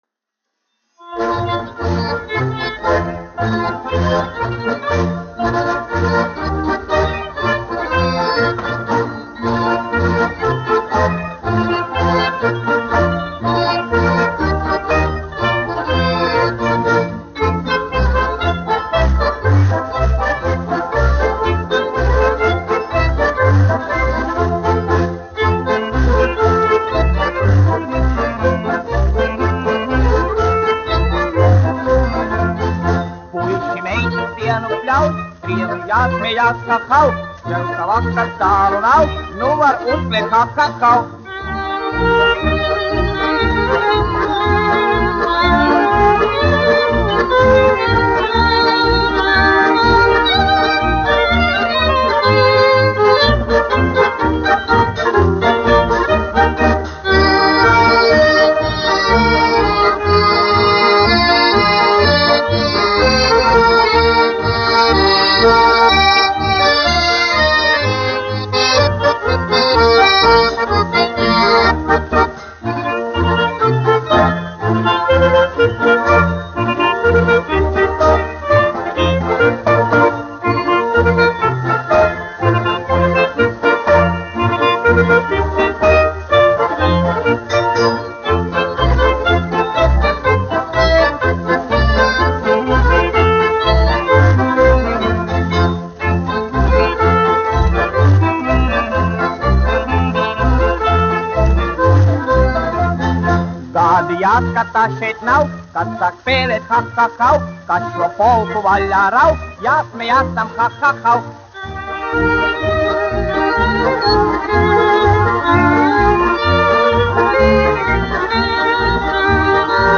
1 skpl. : analogs, 78 apgr/min, mono ; 25 cm
Polkas
Latvijas vēsturiskie šellaka skaņuplašu ieraksti (Kolekcija)